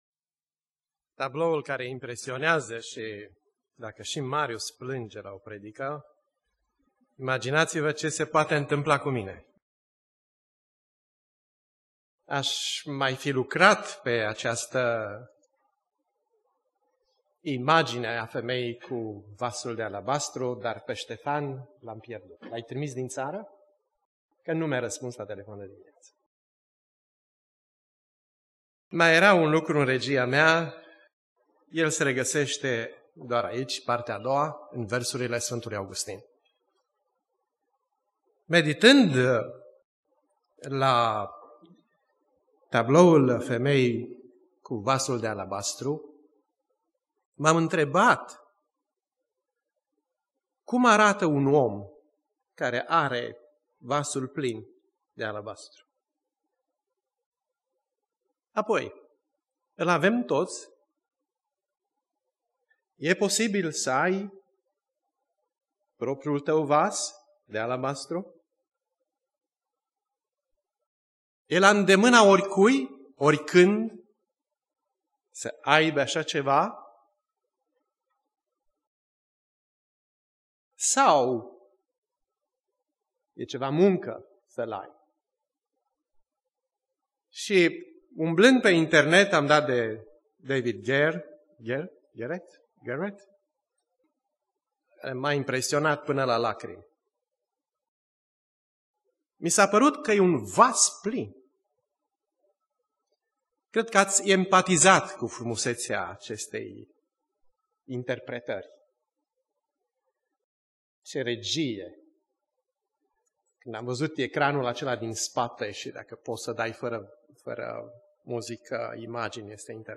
Predica Aplicatie - Matei 26